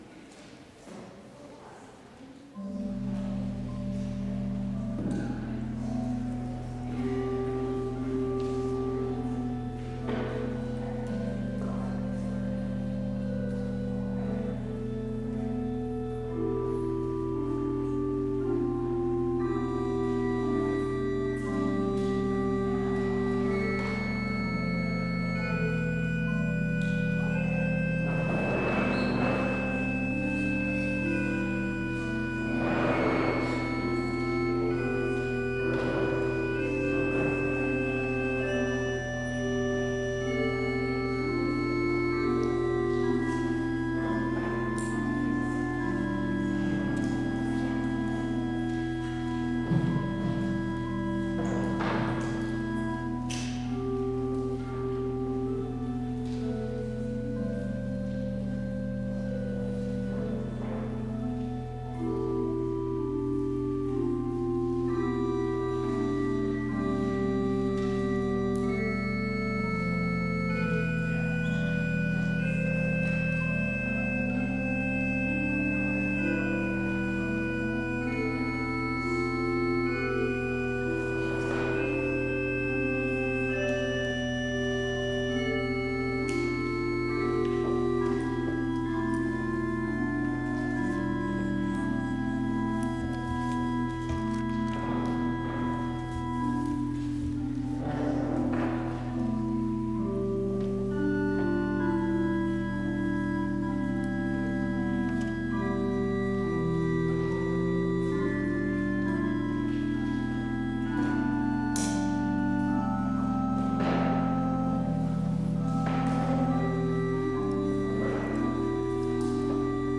Complete service audio for Lent - February 17, 2021